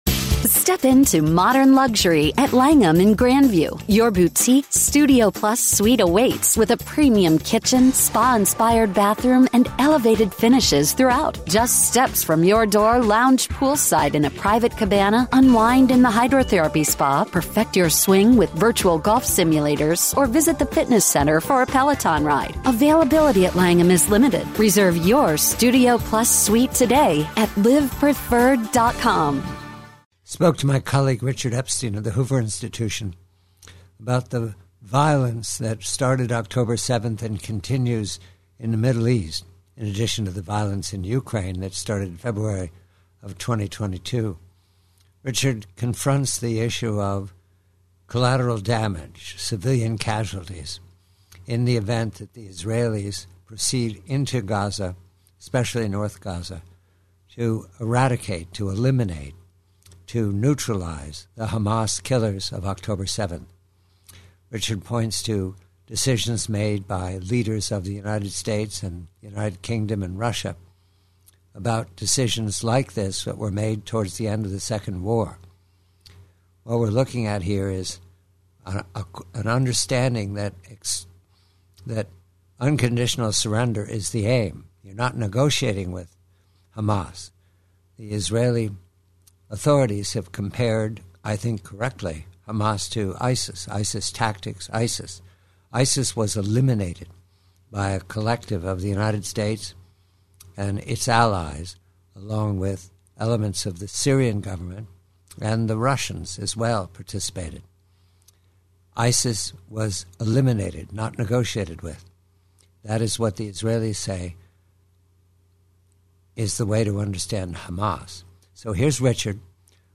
PREVIEW: From a longer conversation, Richard Epstein of the Hoover Institution remarks on the Hamas tactic of using Gazan human shields to fend off the iDF.